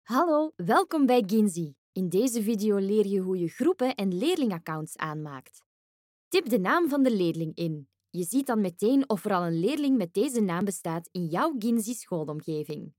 Commercial, Young, Natural, Versatile, Friendly
E-learning